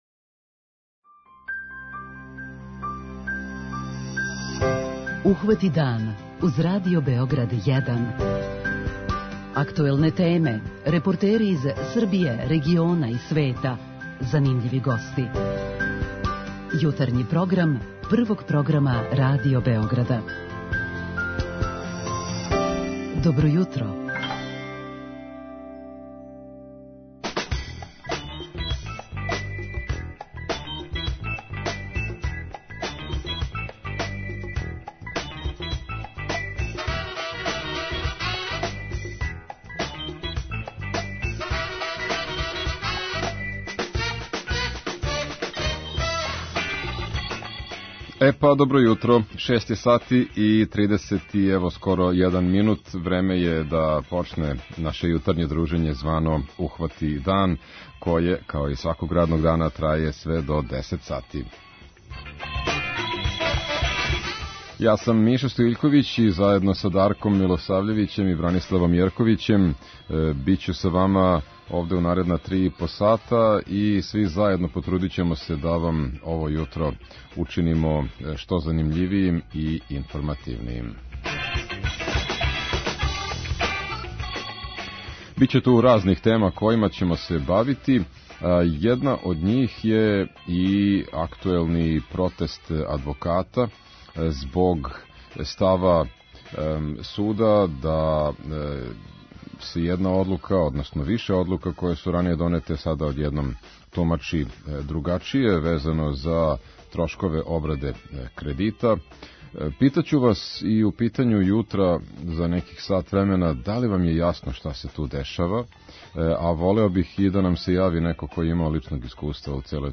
Питаћемо и слушаоце да ли им је нешто јасно у овој правној збрци и да ли је неко од њих имао директно искуство са овом темом.